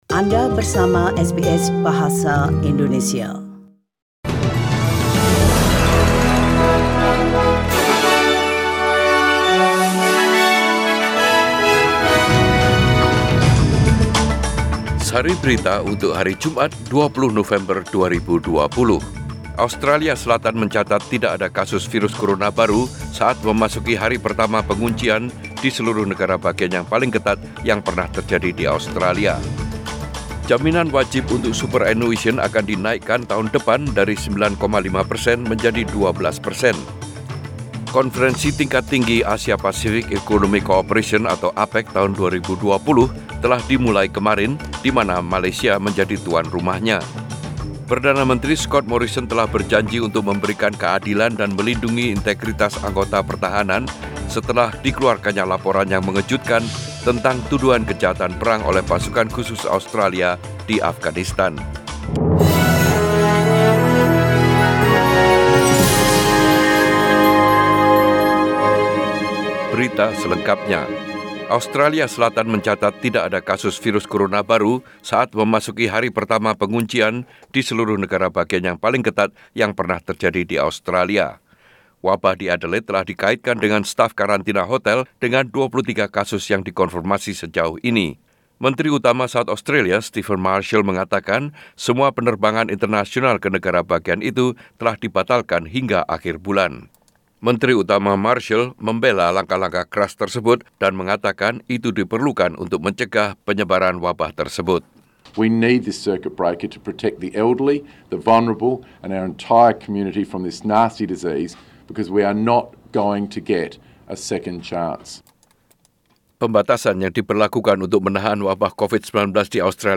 SBS Radio News in Bahasa Indonesia - 20 November 2020